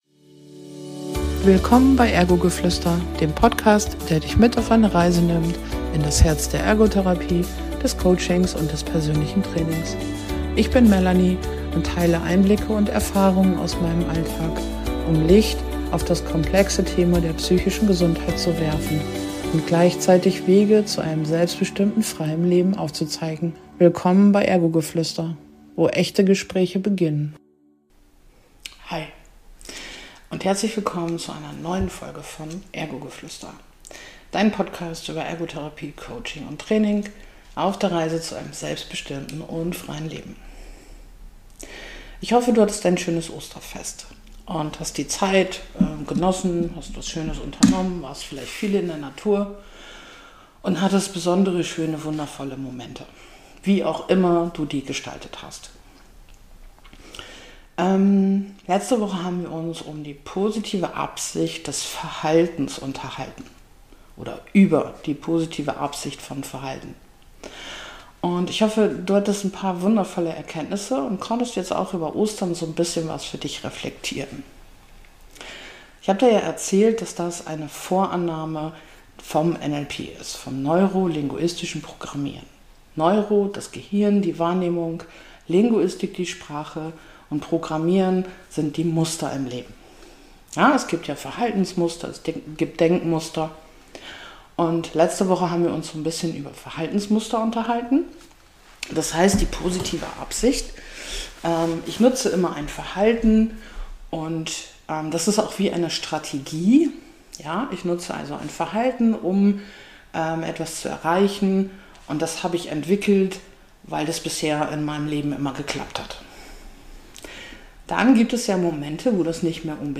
Heute nehme ich Dich mit, auf einen Reflexionsspaziergang am Strand.